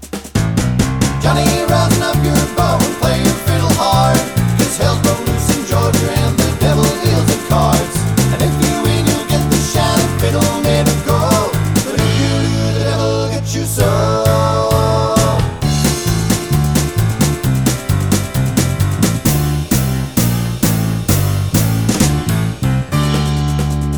no fiddle Country (Male) 3:35 Buy £1.50